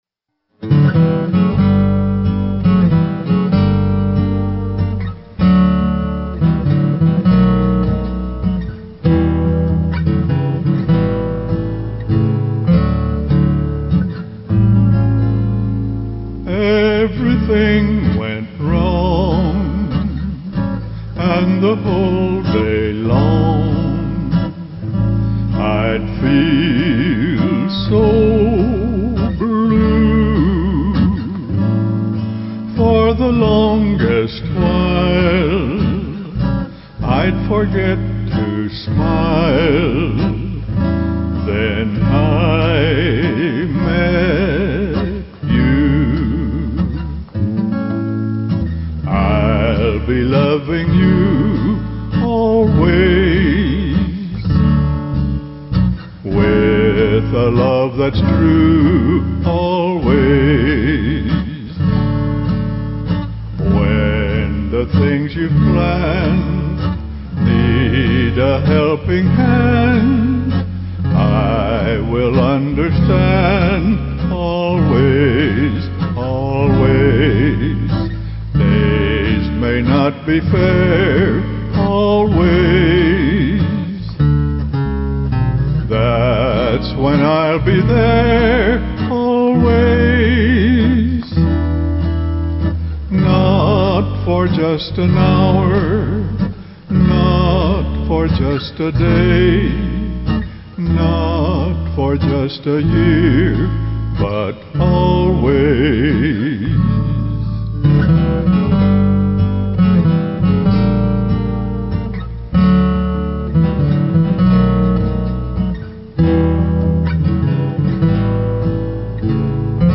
vocals on all songs, except:
all instruments